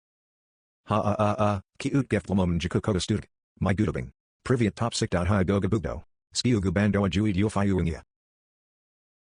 Description: Weird AI text meme man spitting absolutely nothing. gibberish, funny, ai, meme, tiktok.